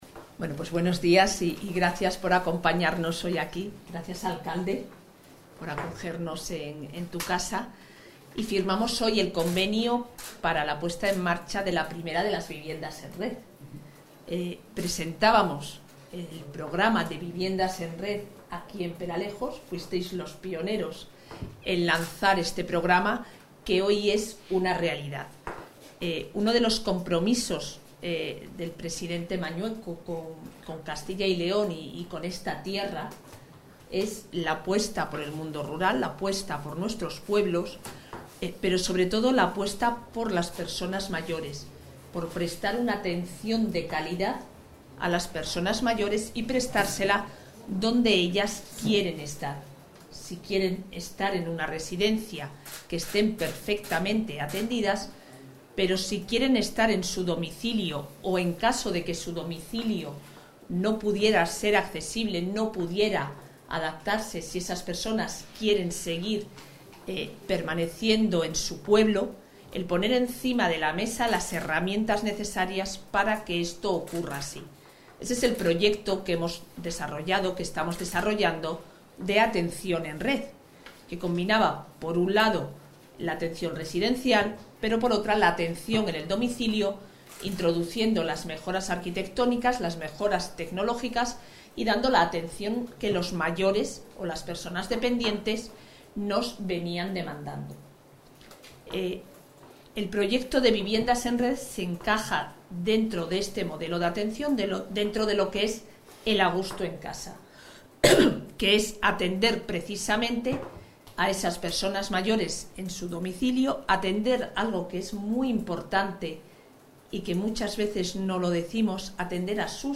Intervención de la vicepresidenta.